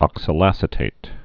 (ŏksəl-ăsĭ-tāt)